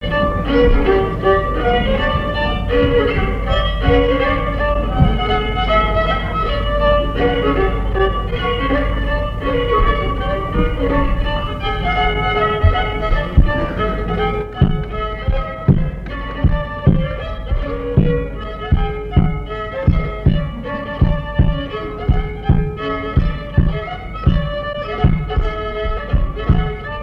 danse : mazurka
Assises du Folklore
Pièce musicale inédite